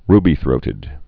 (rbē-thrōtĭd)